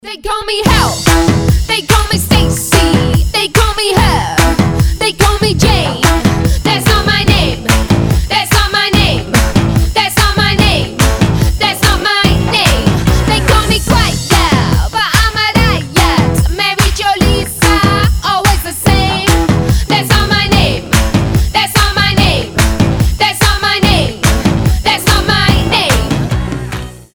• Качество: 320, Stereo
позитивные
веселые
indie pop
Dance-punk